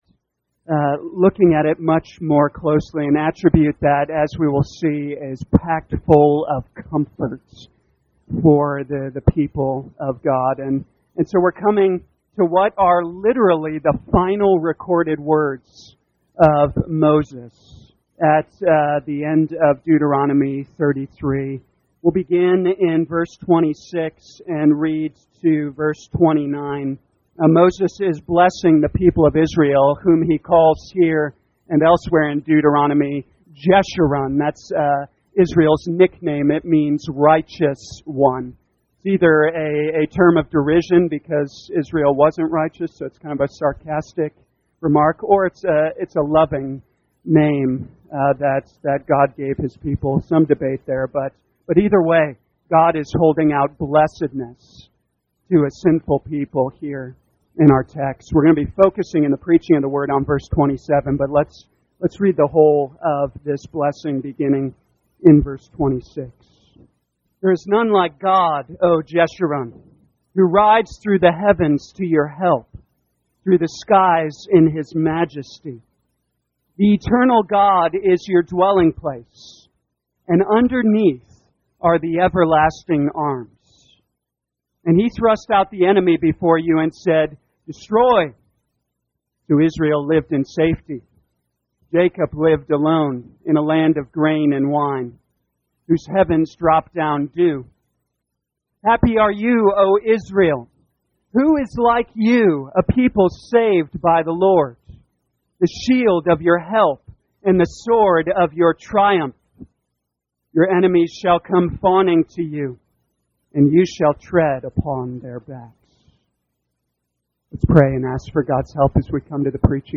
2023 Deuteronomy Knowing God Morning Service Download